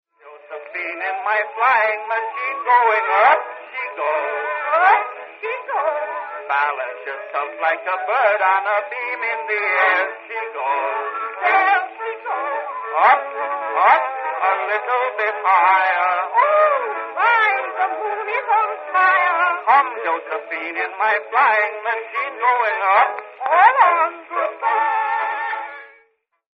Albany Indestructible cylinder #1468